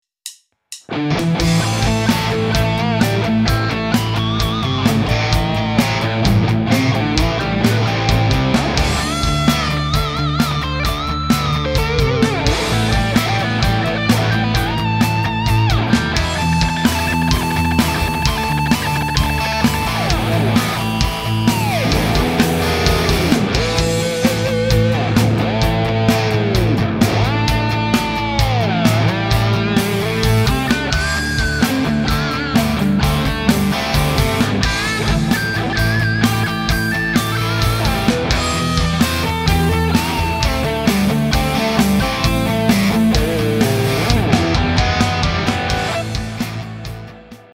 Бэктрэк из нета взял.
Записал кусочек импроверзации. Чистый как есть. Без сторонних обработок. Только минус и гитара через S-GEAR.